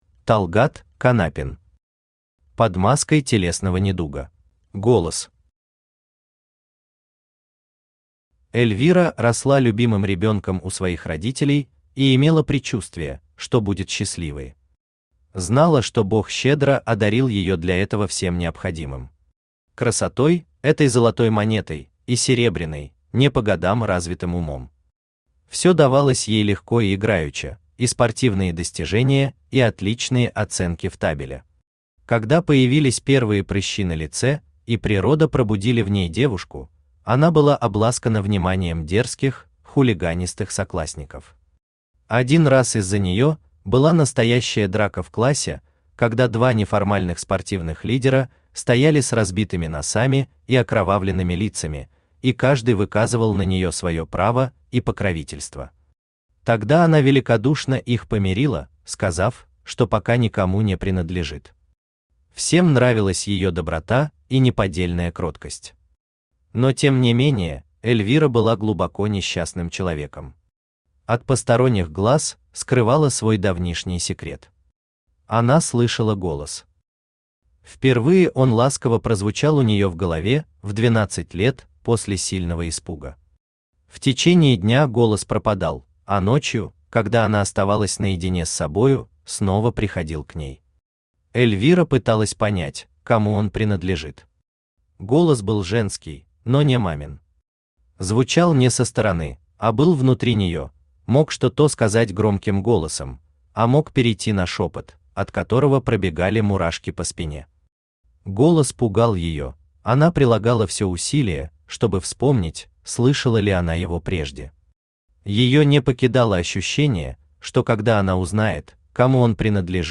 Аудиокнига Под маской телесного недуга | Библиотека аудиокниг
Aудиокнига Под маской телесного недуга Автор Талгат Канапин Читает аудиокнигу Авточтец ЛитРес.